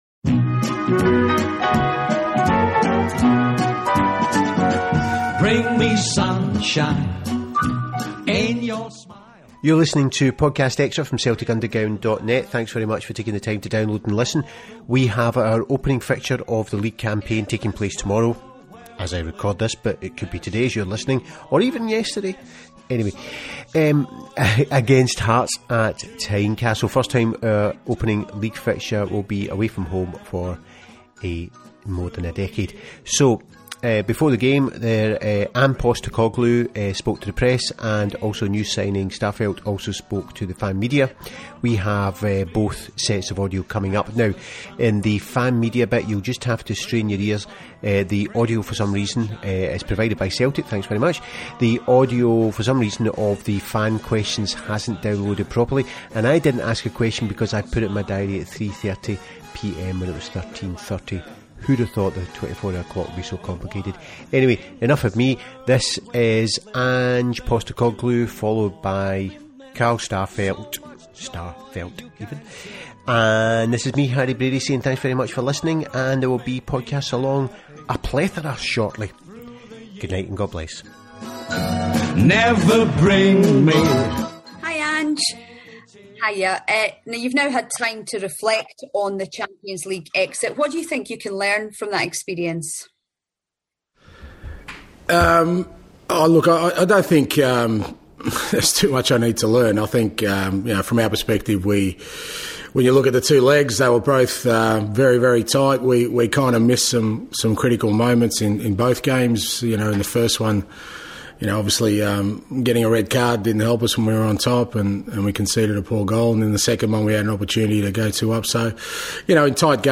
Headliner Embed Embed code See more options Share Facebook X Subscribe Podcast Xtra Ange & the starman On Saturday31st July Celtic commence the new league season away at Tynecastle. The current state of the first team squad has some fans concerned but we have the new Swede and an update on the new Japanese striker in these pre-game interviews from Ange Postecoglou and Carl Starfelt.